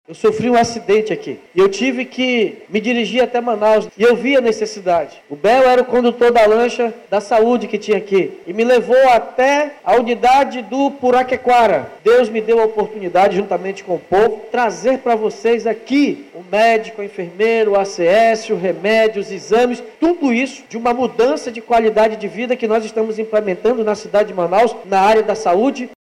Durante a inauguração, o prefeito de Manaus, Davi Almeida, relatou uma situação que passou na zona rural, onde pôde ver de perto a precariedade da saúde.